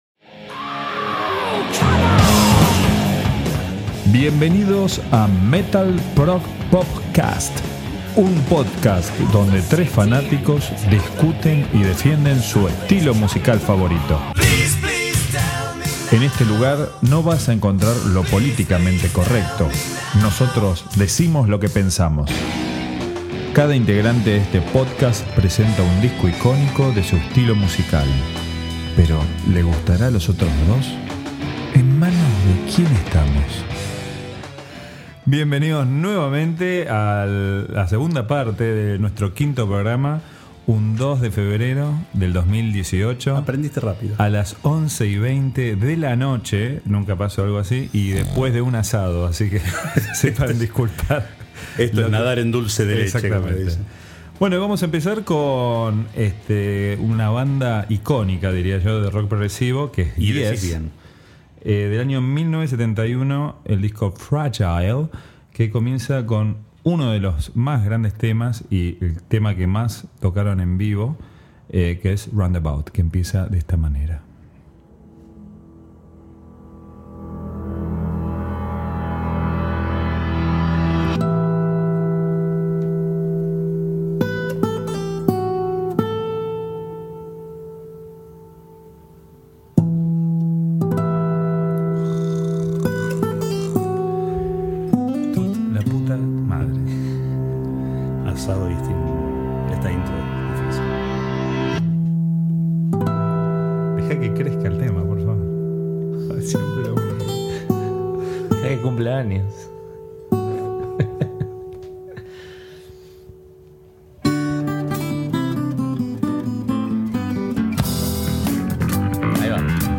En MetalProgPop Cast nos juntamos 4 amigos para hablar y discutir sobre música.